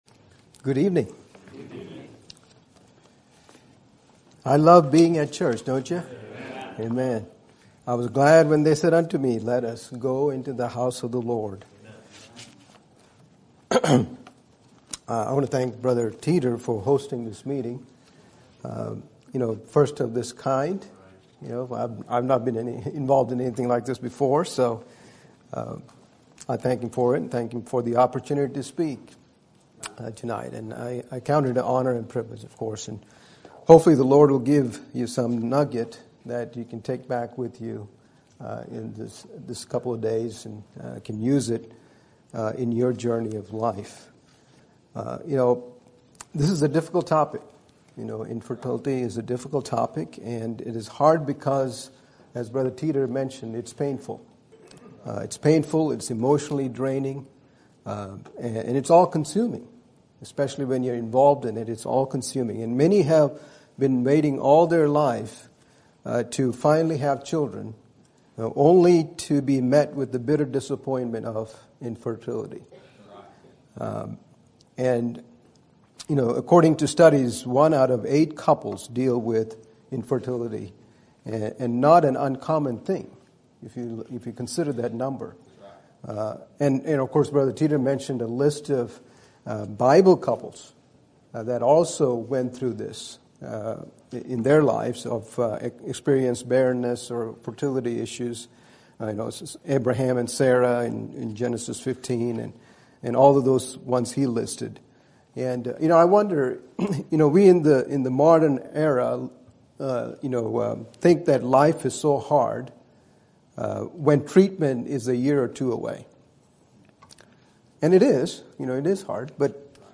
Sermon Topic: Lonely Road Conference Sermon Type: Special Sermon Audio: Sermon download: Download (21.16 MB) Sermon Tags: Lonely Infertility Adoption Couples